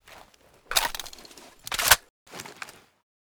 m1a1_new_reload.ogg